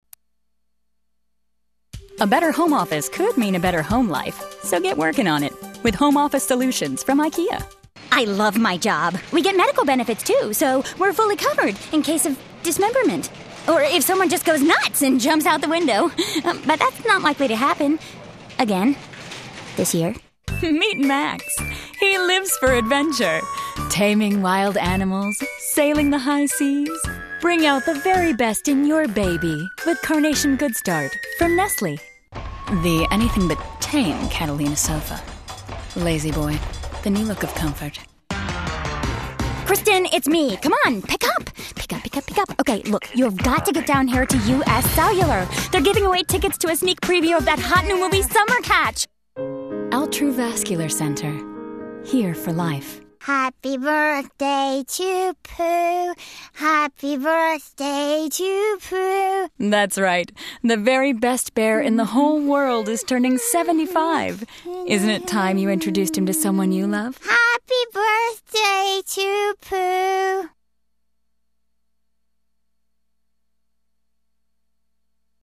Voiceover Demo